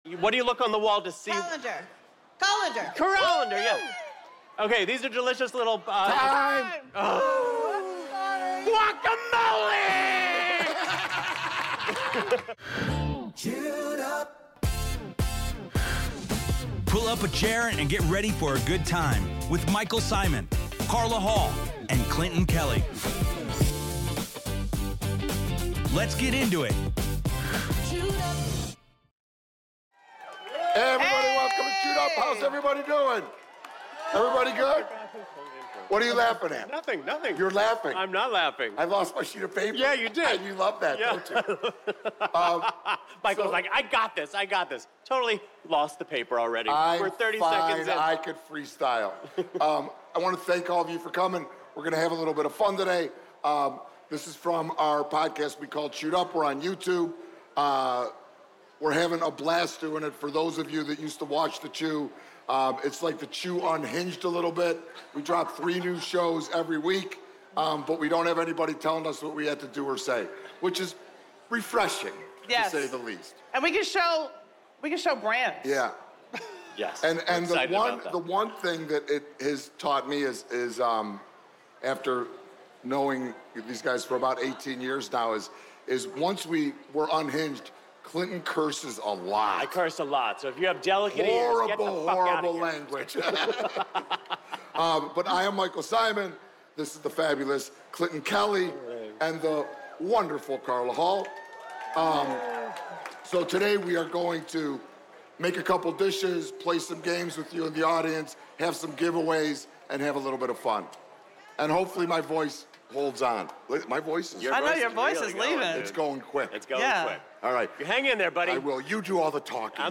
This episode brings the Chewed Up crew to Miami for a high-energy, audience-filled show packed with laughs, games, and bright, citrus-forward cooking. Michael Symon, Carla Hall, and Clinton Kelly kick things off with their signature unfiltered banter before diving into a lively round of “Chew It or Screw It,” where the audience weighs in on wild Florida foods like iguana pizza, python sausage, and alligator ribs.